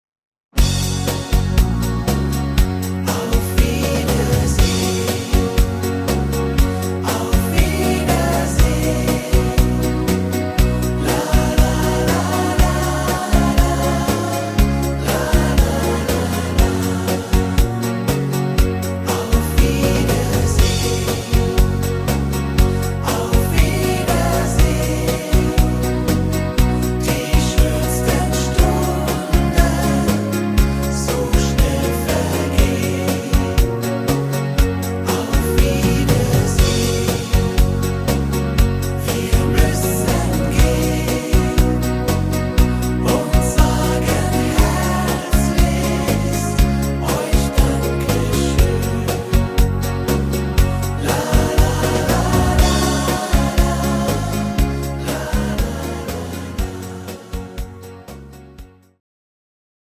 Rhythmus  8 Beat
Art  Deutsch, Volkstümlicher Schlager